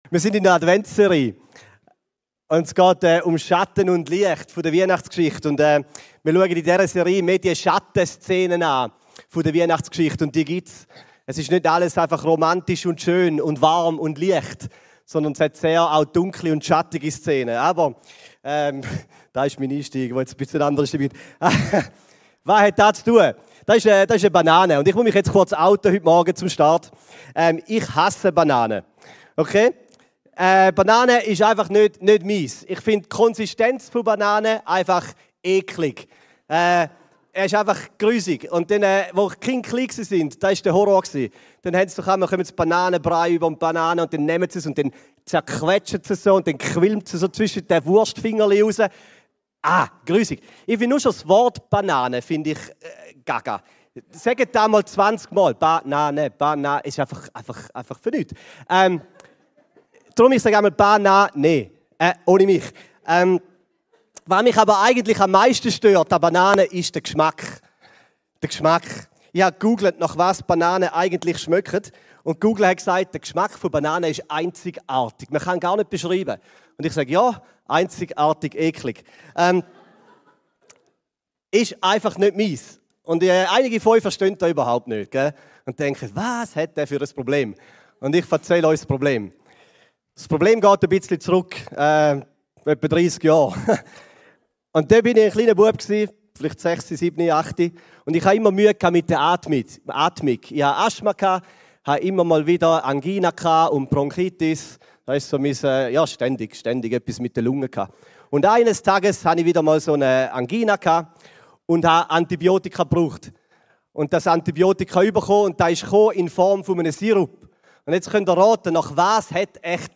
In der Predigt am Sonntag tauchen wir ein in den fast übersehenen Teil von Weihnachten: die dramatische Flucht von Josef, Maria und dem kleinen Jesus nach Ägypten. Der Retter der Welt beginnt sein Leben nicht im Glanz, sondern als Flüchtling.